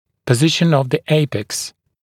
[pə’zɪʃn əv ðə ‘eɪpeks][пэ’зишн ов зэ ‘эйпэкс]положение апекса, положние верхушки корня